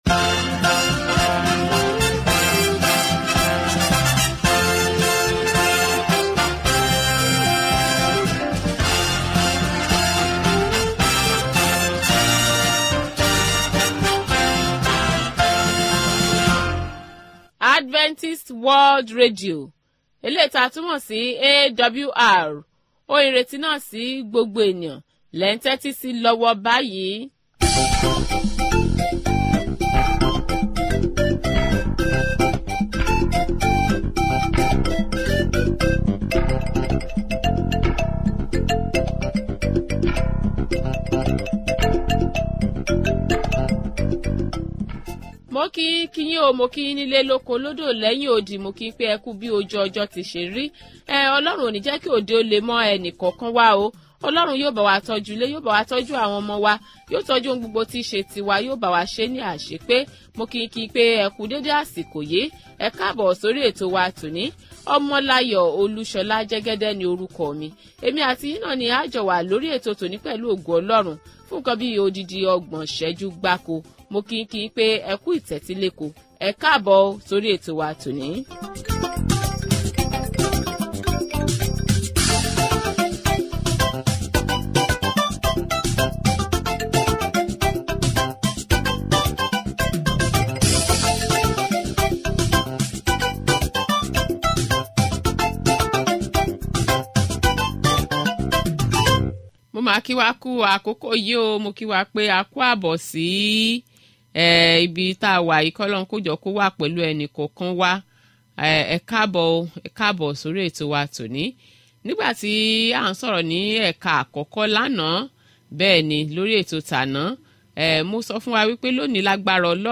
Daily Yoruba radio programs from Adventist World Radio